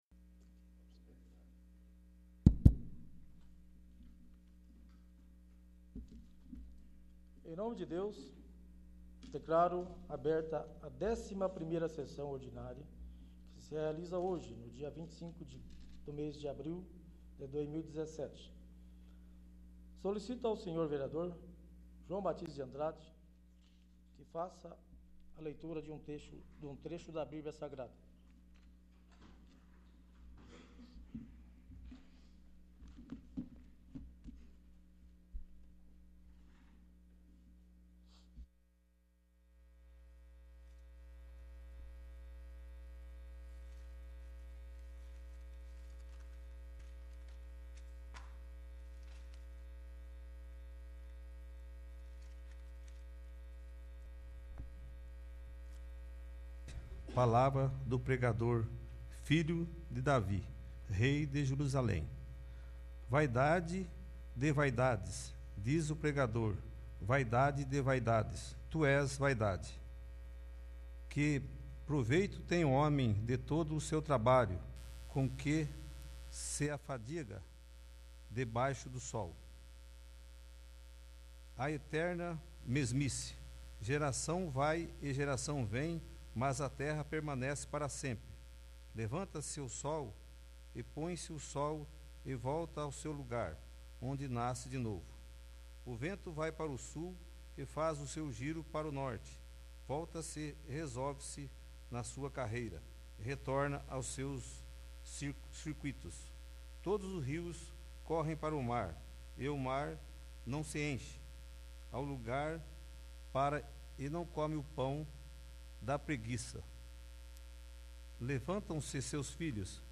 11º. Sessão Ordinária